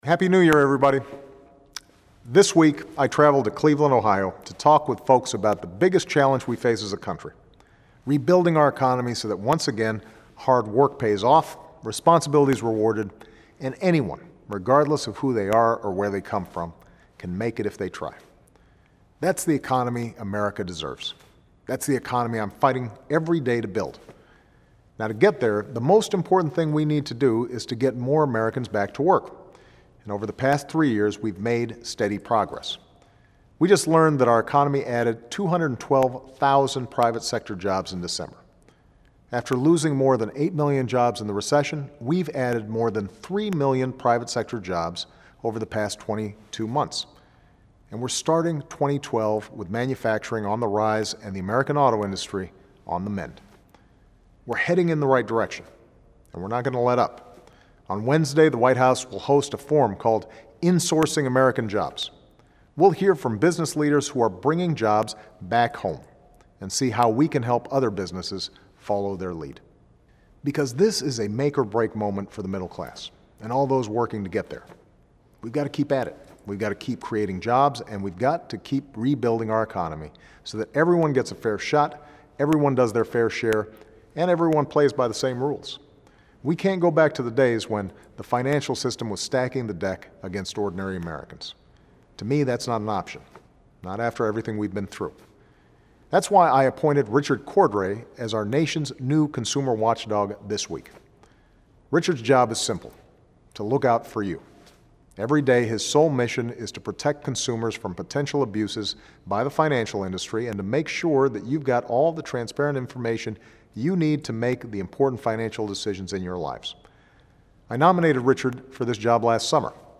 演讲听力材料01.08
Remarks of President Barack Obama